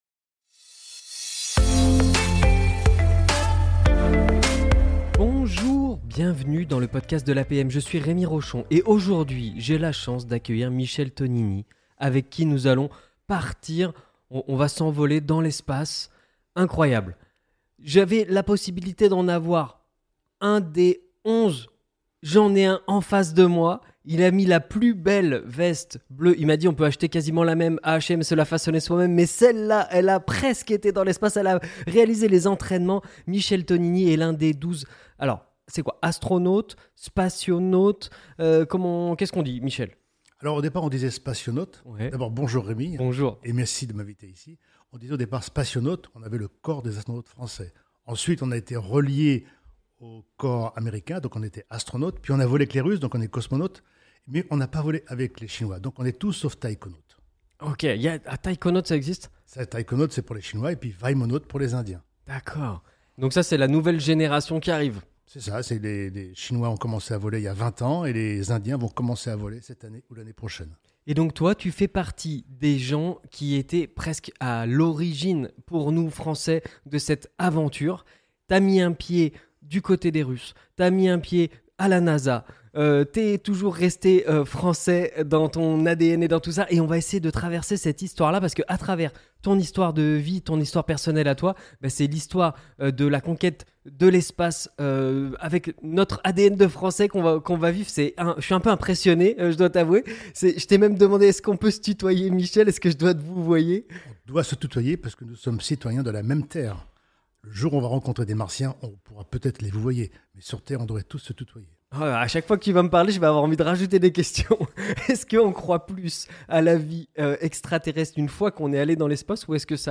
Ici, vous trouverez des interviews avec des experts reconnus dans leurs domaines.